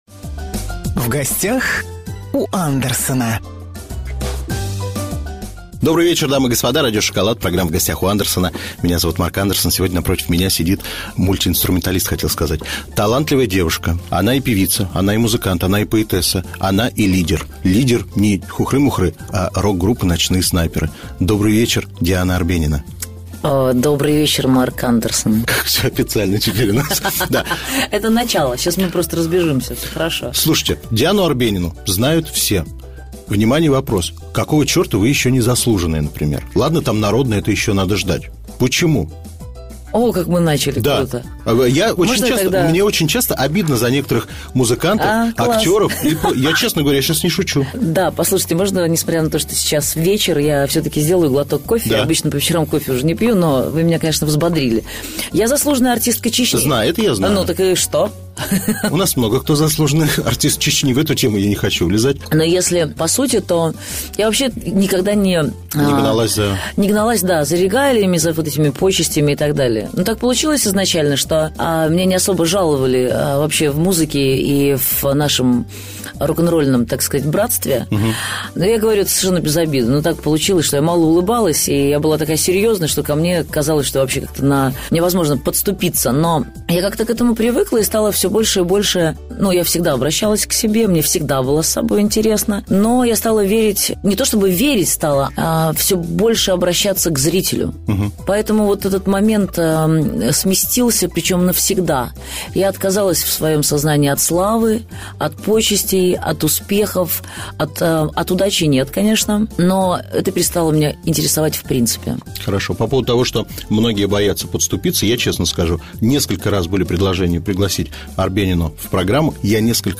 Интервью на Radio Chocolate (98 FM)